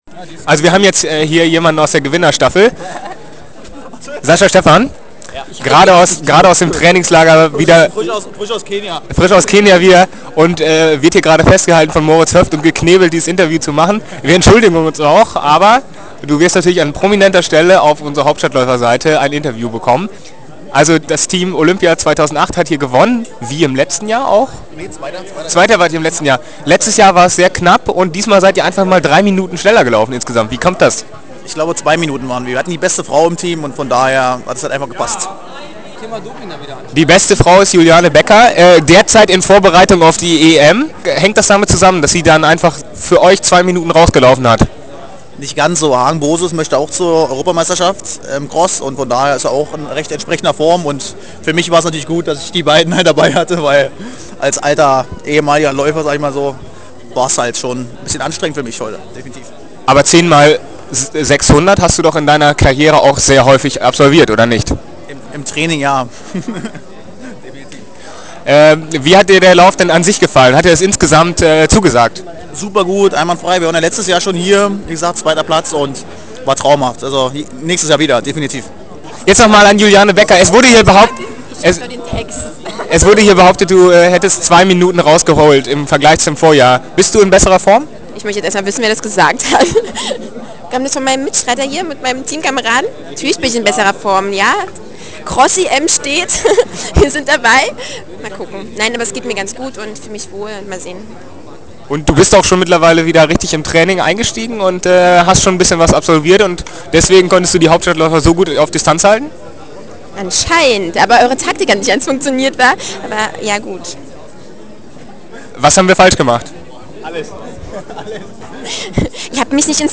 interview-team2008.mp3